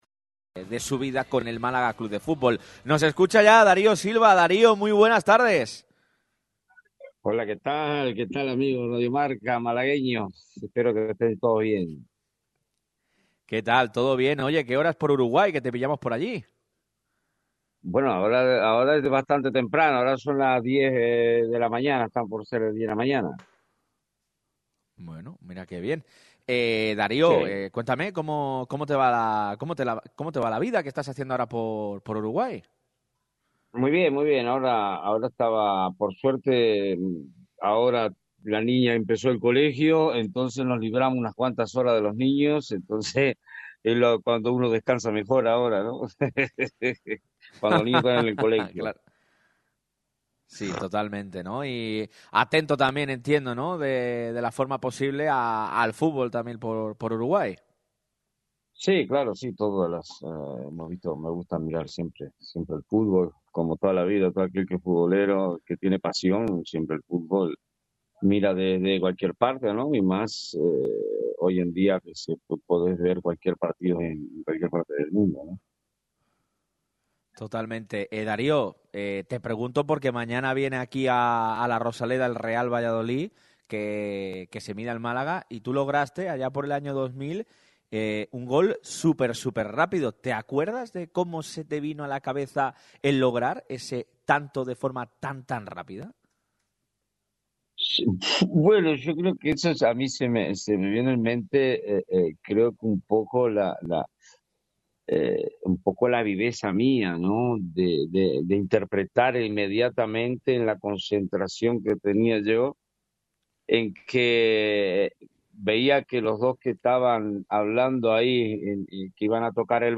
Con motivo del partido entre Málaga y Real Valladolid, Darío Silva ha estado en Radio MARCA Málaga con motivo de su recordado gol ante el conjunto pucelano.